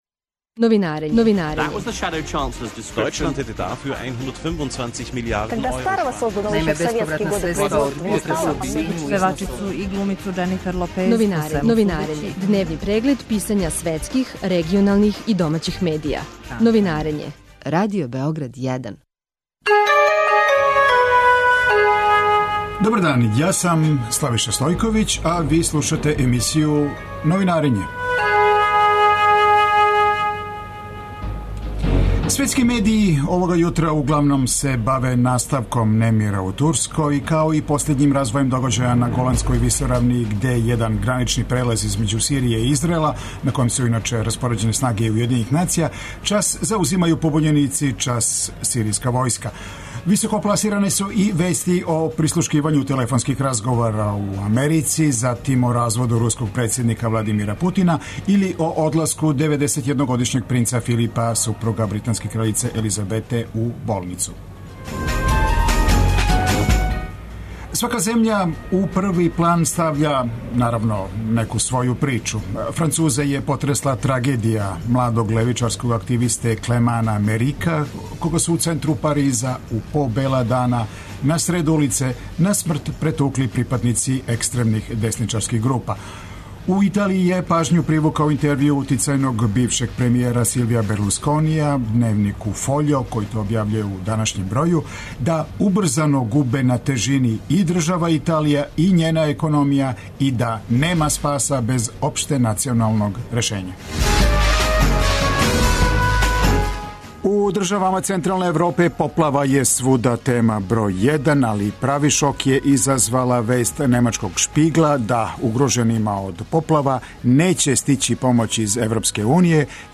Све епизоде серијала Аудио подкаст Радио Београд 1 Спортско вече